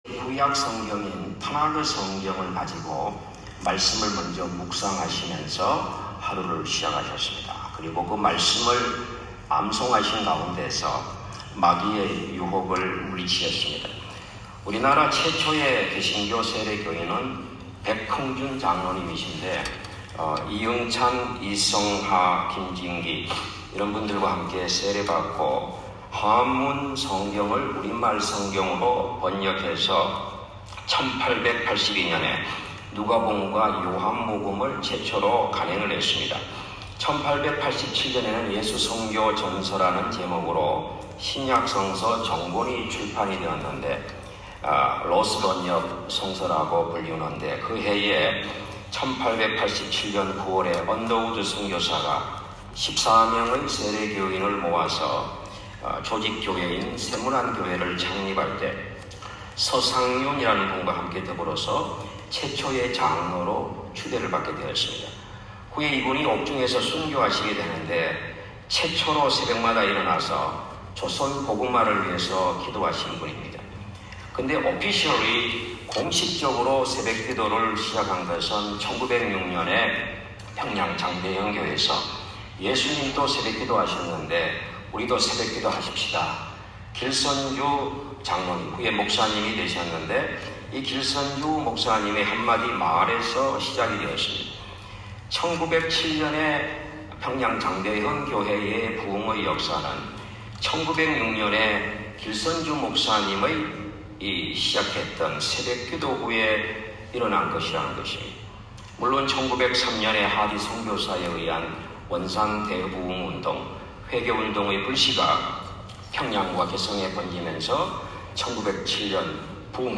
9월-18일-월요일-새벽-원주-연합성회-.m4a